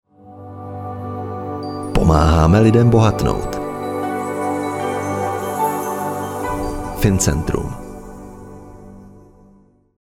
Mužský voiceover do Vašich videí
Nabízím mužský voiceover středního věku.